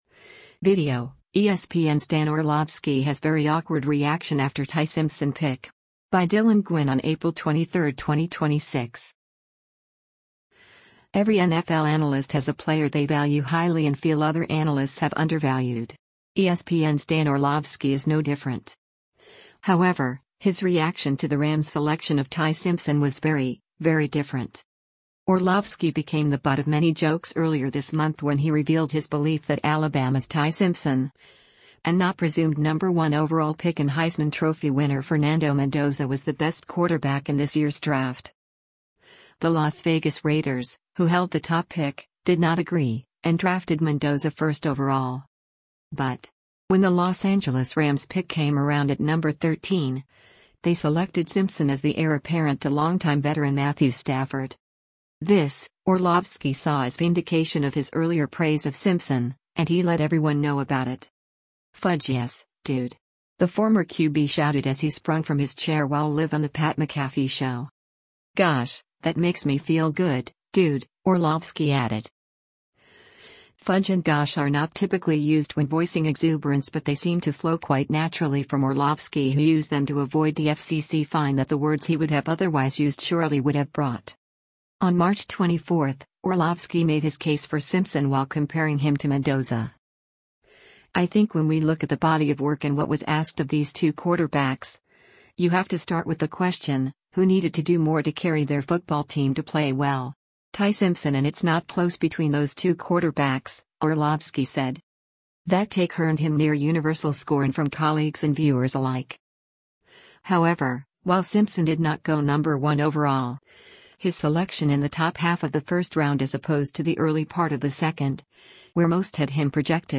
“Fudge yes, dude!” the former QB shouted as he sprung from his chair while live on the Pat McAfee Show.